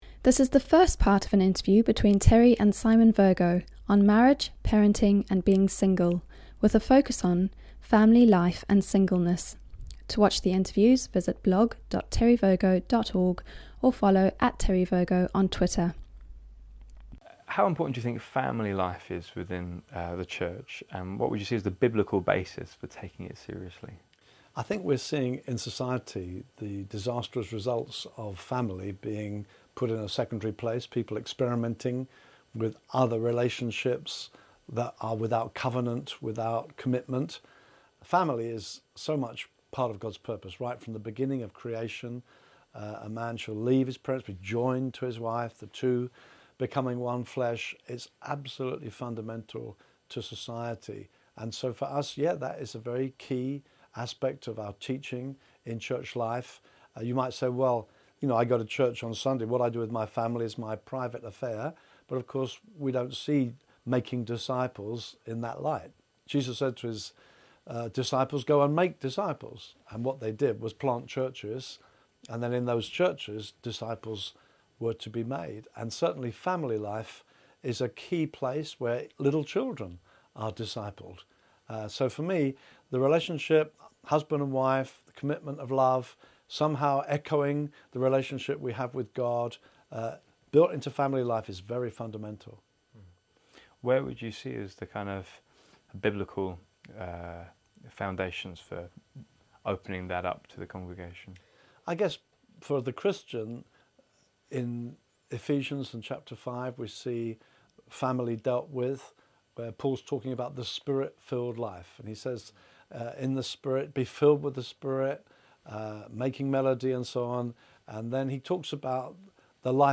Interview+7a.+On+family+life+and+singleness.mp3